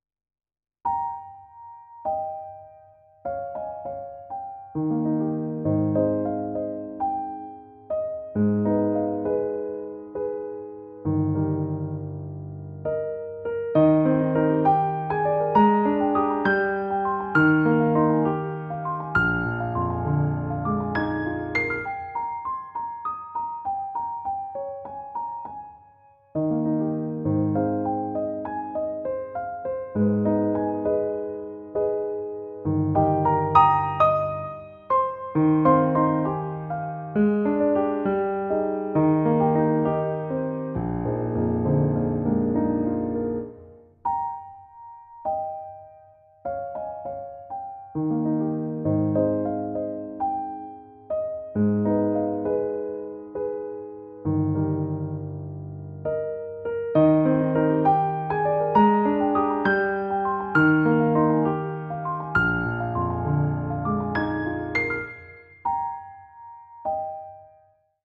Interestingly the main theme sounds almost identical to the original, albeit there are many subtle differences.
Although the main theme is a blatant quote, the other parts are much less obviously derived from the famous composer.